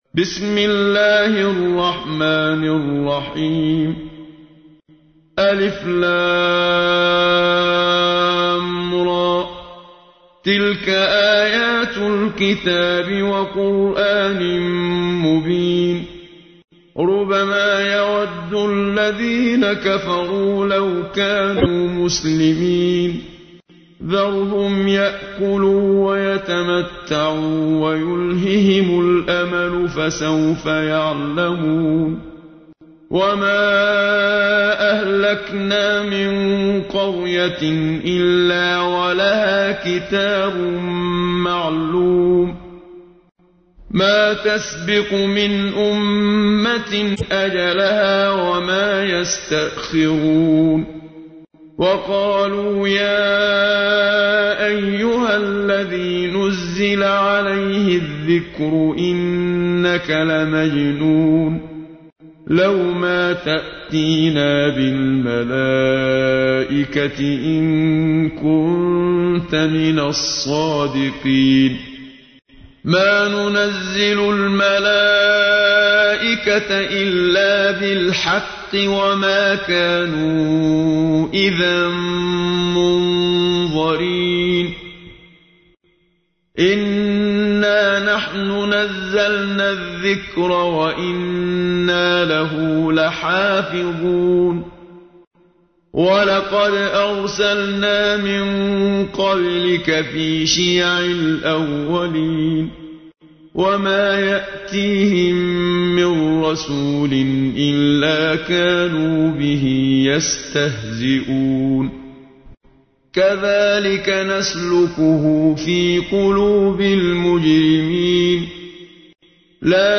تحميل : 15. سورة الحجر / القارئ محمد صديق المنشاوي / القرآن الكريم / موقع يا حسين